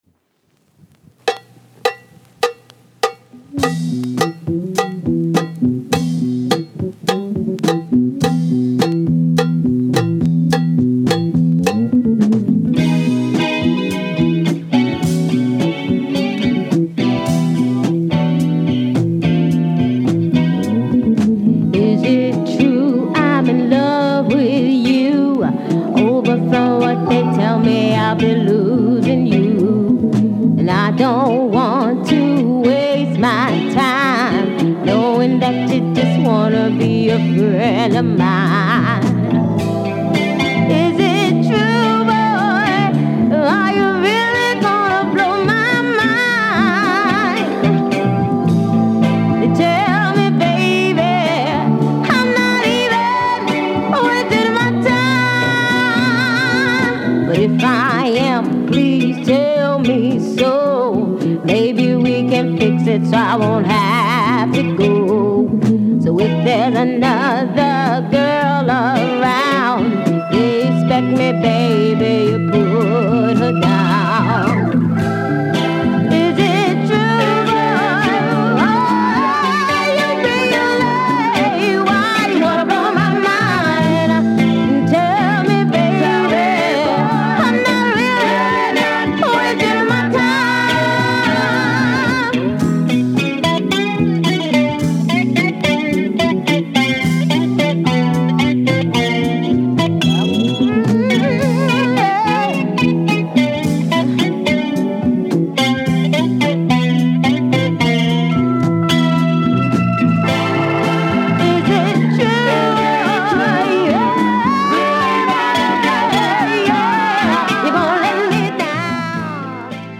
入手難 RARE MODERN SOUL FUNK!!
モダンダンサー 唯一シングル 試聴
美しいメロディもさる事ながら、嫋やかなリズムに乗って情感豊かに歌い上げるビューティフル・ソウル
サイケ感を纏った爽快ソウルフルでファンキーな
軽微な薄スレを少し確認できる程度でノイズ極少、再生ともに十分良好です。